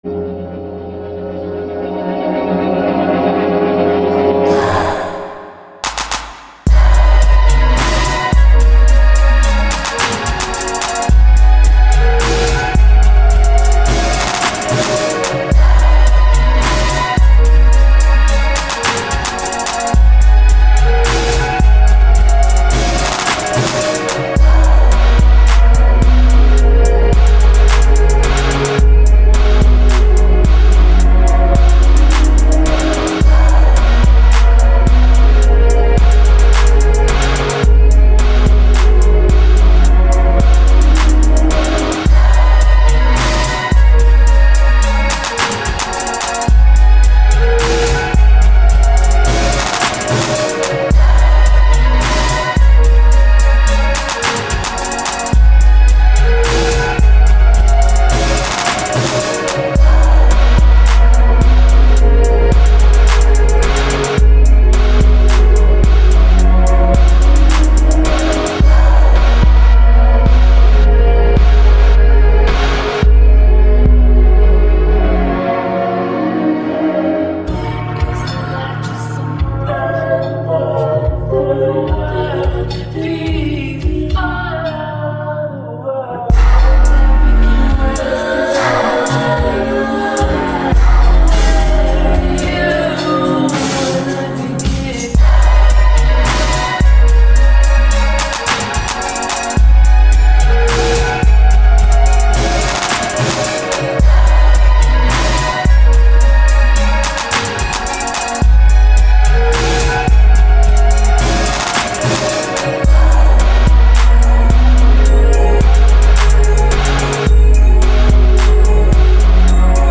Very original instrumental EP